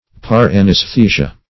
Search Result for " par-anesthesia" : The Collaborative International Dictionary of English v.0.48: Para-anaesthesia \Par`a-an`aes*the"si*a\, Par-anesthesia \Par`-an`es*the"si*a\, n. [NL.; para- + an[ae]sthesia.]
par-anesthesia.mp3